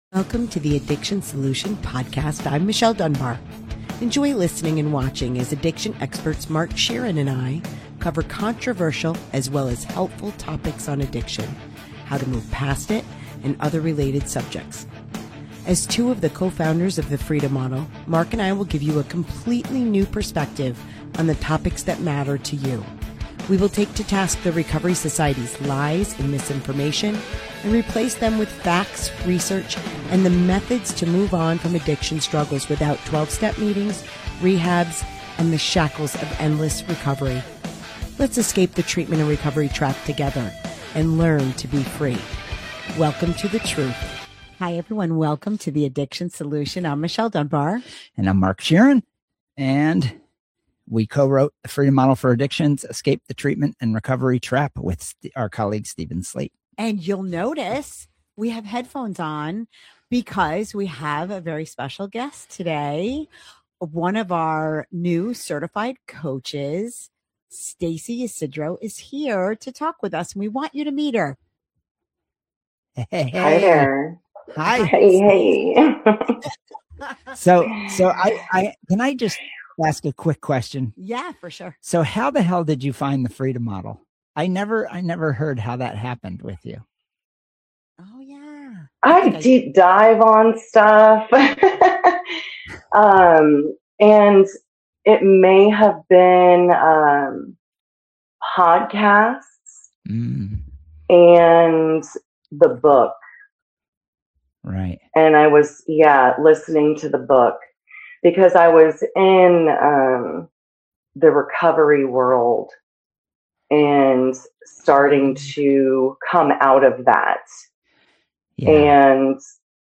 Now she helps individuals break free from limiting beliefs and fully embrace who they are – without shame and labels. This is an honest, eye-opening conversation about reclaiming your life, your choices, and your freedom.